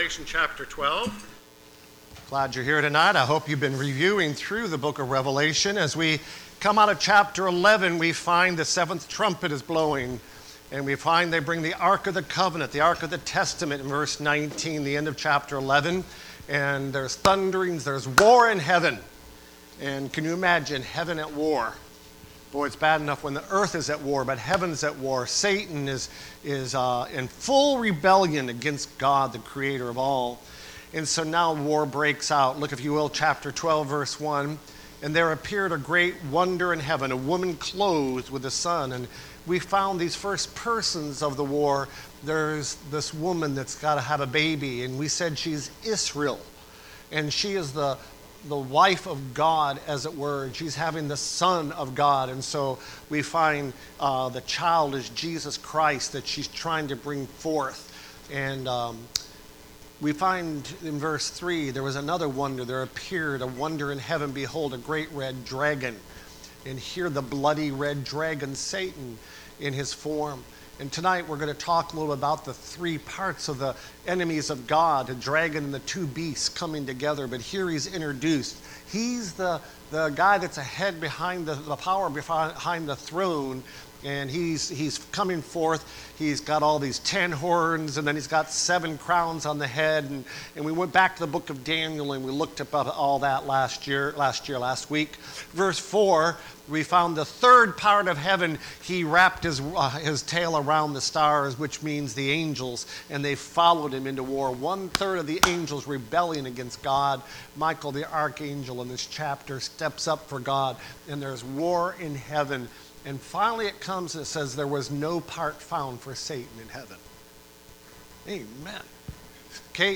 The Revelation Service Type: Sunday Morning Preacher